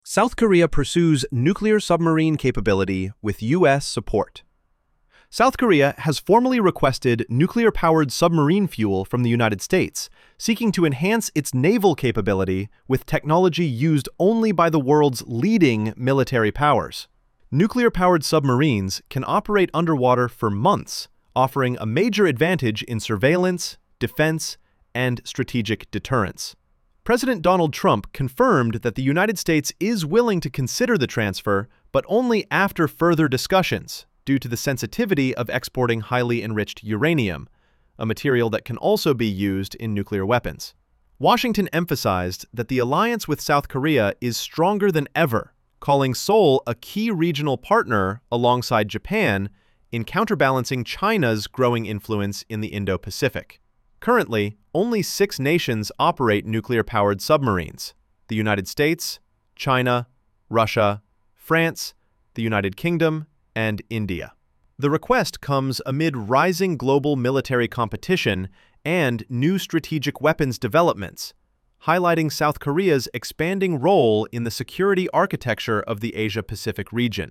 International Defense Brief · MP3 · ~45–60 seconds